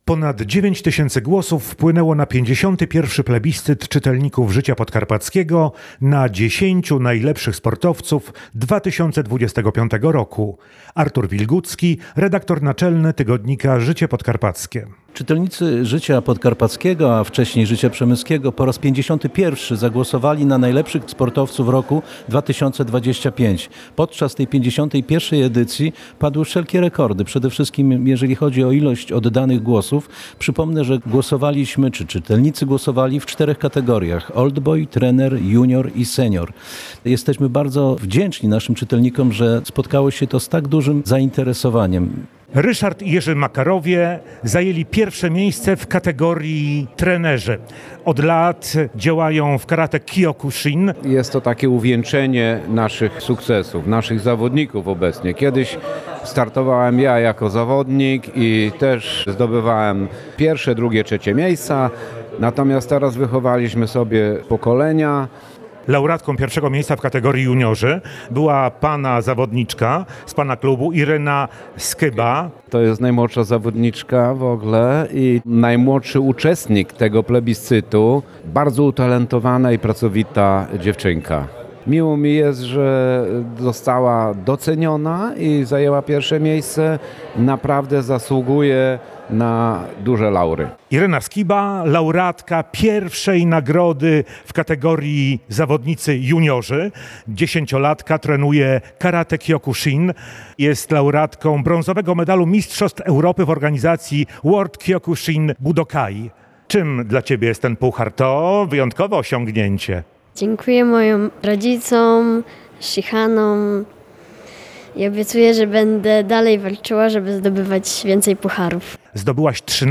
Sport • W sali widowiskowej Muzeum Narodowego Ziemi Przemyskiej odbyła się 51. Gala Plebiscytu na 10 Najlepszych Sportowców 2025 roku Tygodnika Życie Podkarpackie.